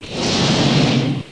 1 channel
00444_Sound_GeisterBahnEin.mp3